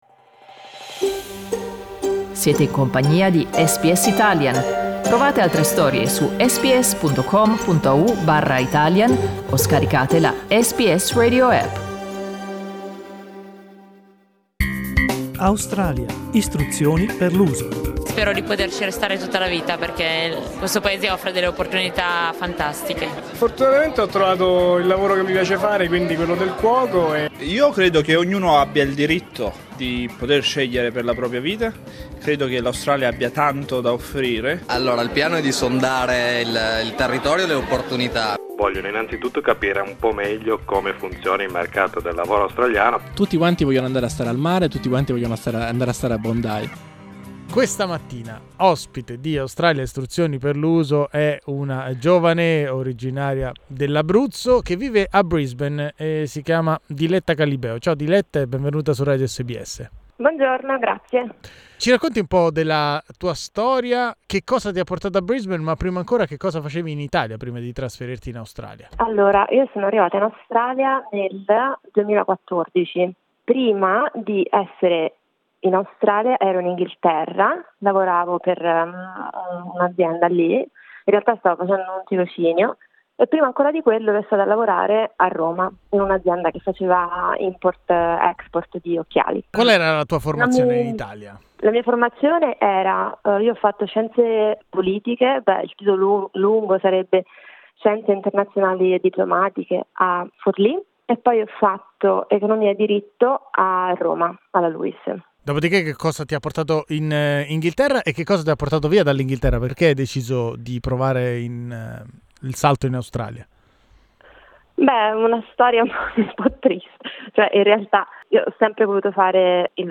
In questa intervista con SBS Italian